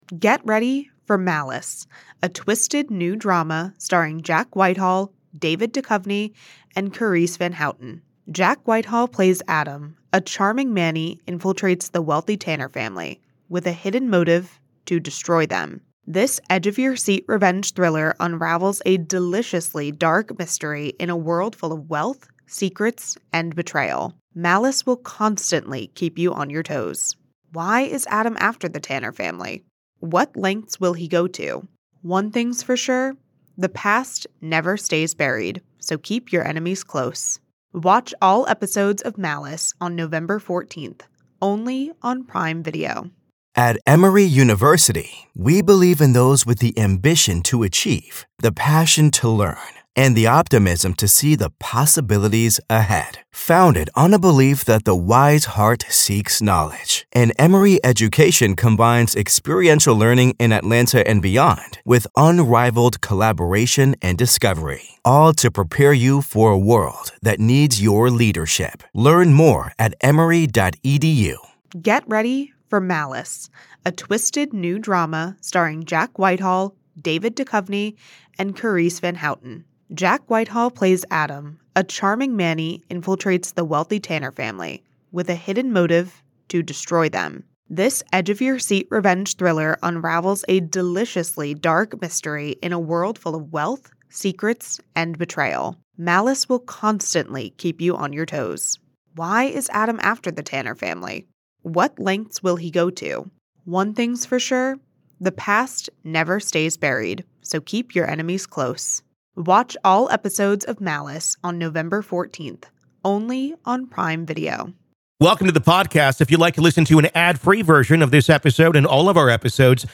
retired FBI Special Agent